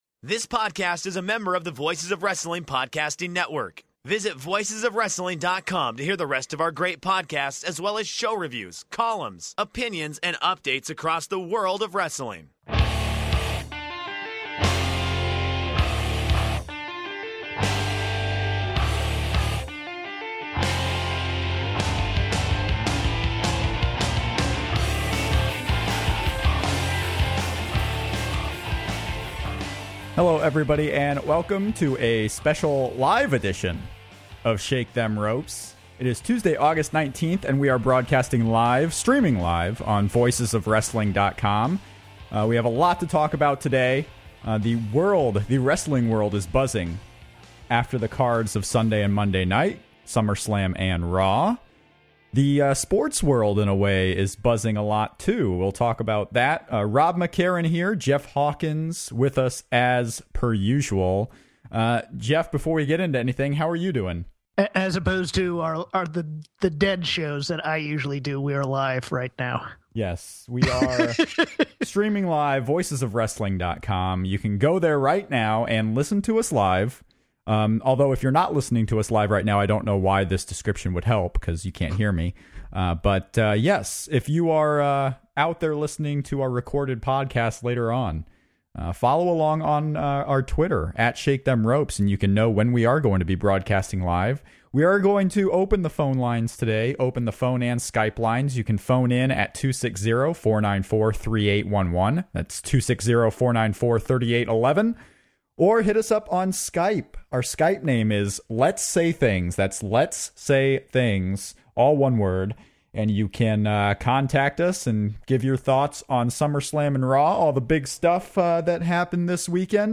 LIVE!!